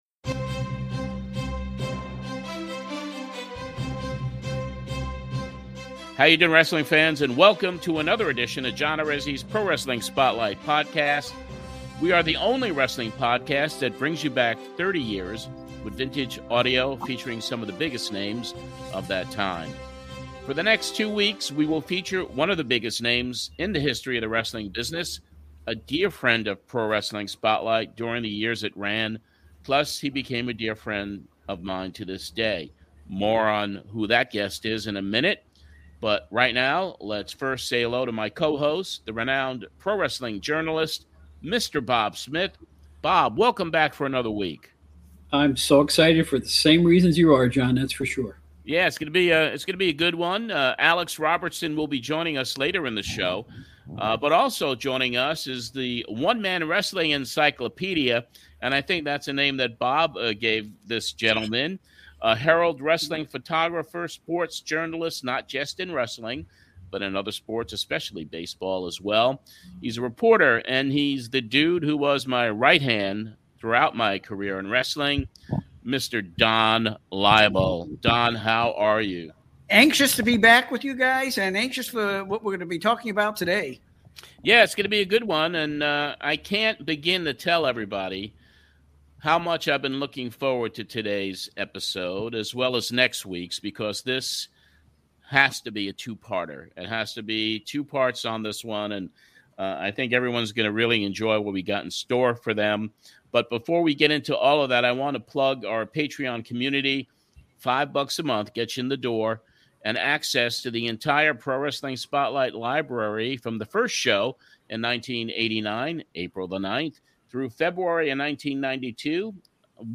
This episode will break our regular format - as we welcome special guest MICK FOLEY. We cover several appearances by Mick, as CACTUS JACK from his first appearance on the Spotlight, beginning in September, 1989 and running through January 12th, 1992 on WEVD.